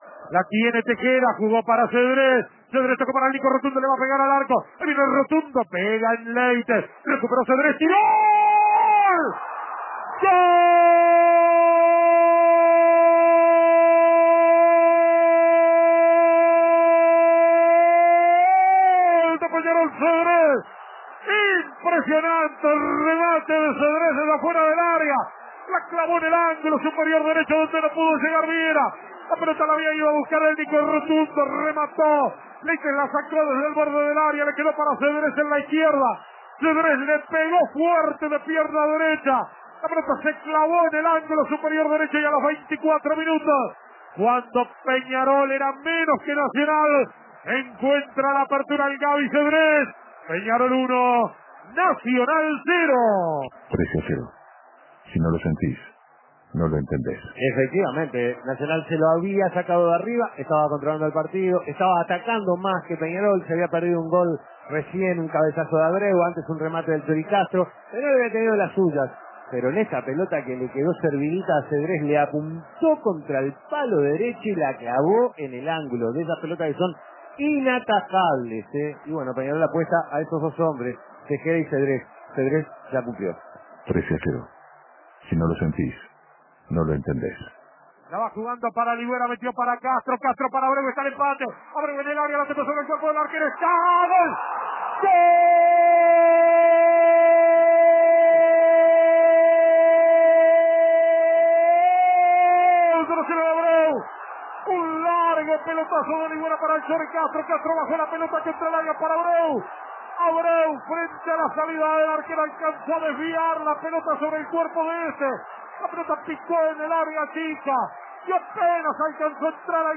Radiodeportivo Escuche los goles del partido clásico